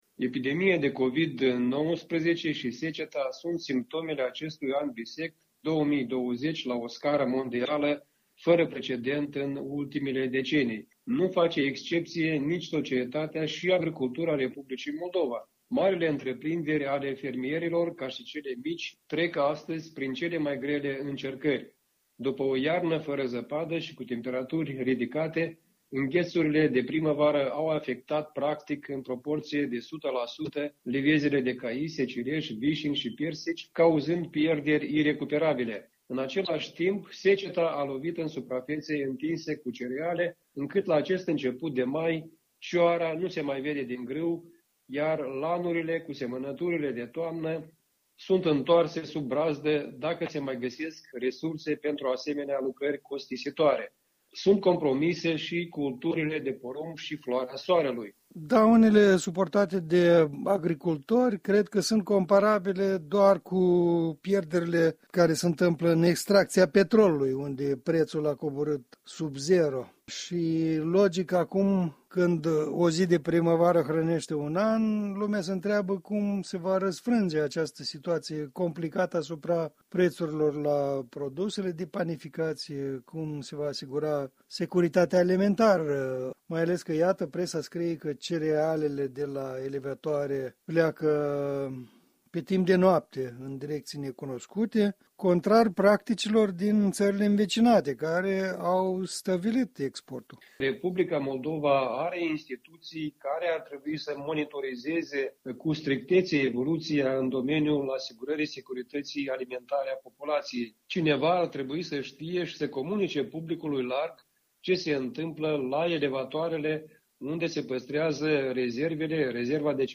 Un punct de vedere săptămânal în dialog.